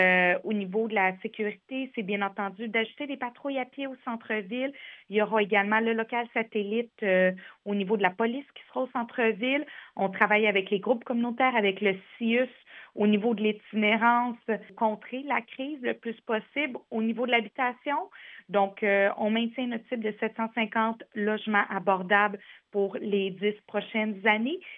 On peut entendre Mme Bourdon :